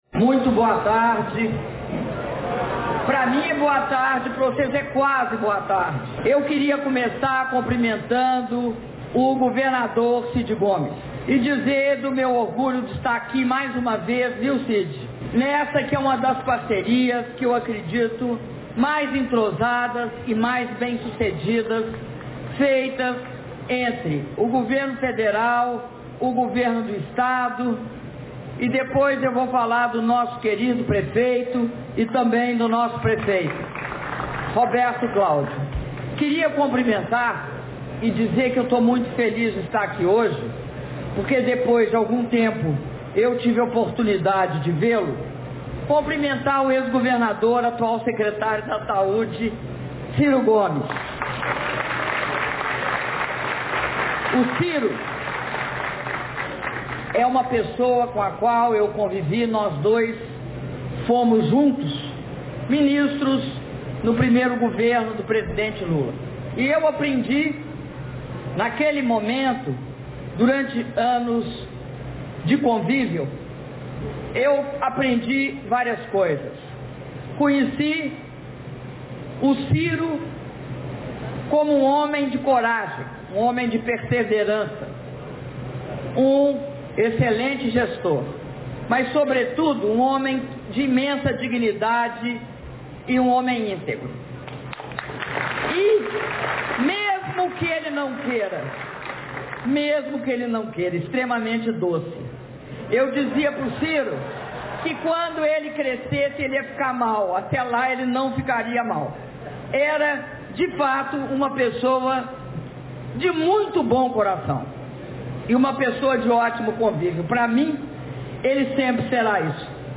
Áudio do discurso da Presidenta da República, Dilma Rousseff, durante cerimônia de anúncio de investimentos do PAC2 Mobilidade Urbana - Fortaleza/CE